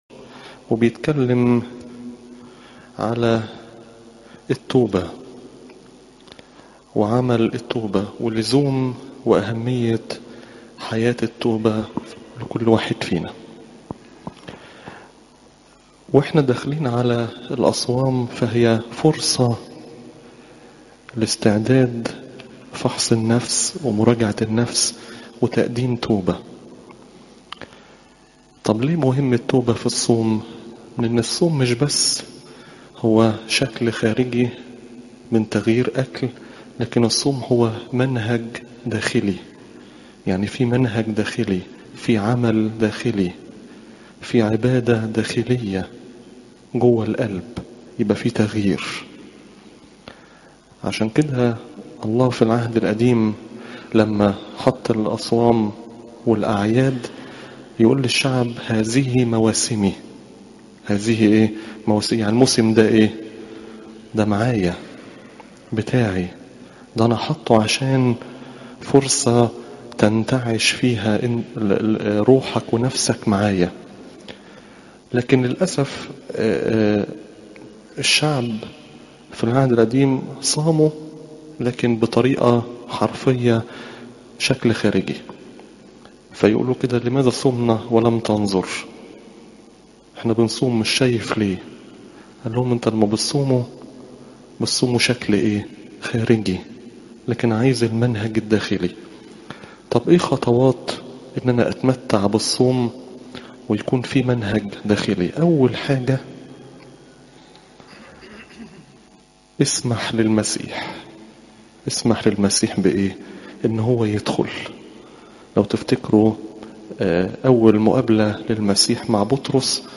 عظات قداسات الكنيسة سبت الرفاع الصوم الكبير (لو 13 : 1 - 5)